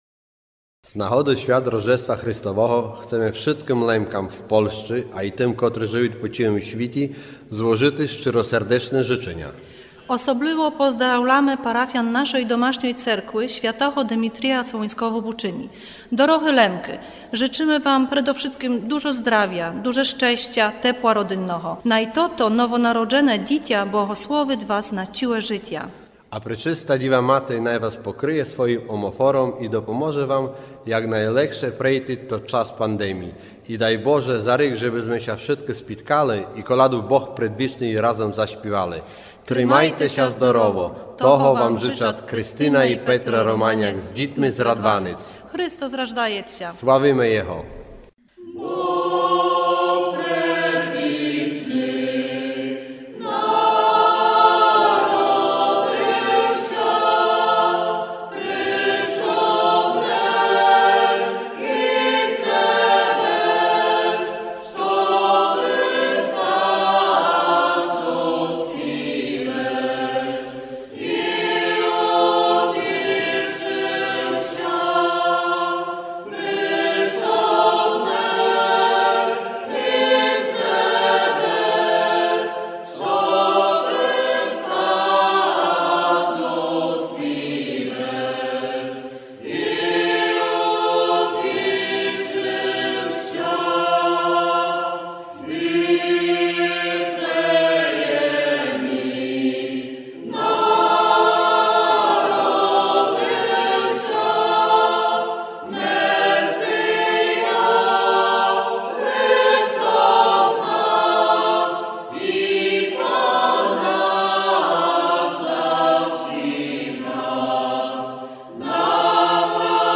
Концерт коляд в выконаню церковного хору в Бучыні – середа, 18.30 год.
Хоц члены хору то в векшости аматоры, якы церковным співом занимают ся лем, а може аж з потребы духа, спів тот трактуют як найвекшый дар од Бога і завдякы тому дарови можут співати на його хвалу наштоден і од свята.
На Святый Вечер запрашаме слухати концерт коляд і желаня для слухачів радия з нагоды Рождества Христового, якы передадут хористы.
zajawa_koncertu_kolad_z_buczyny.mp3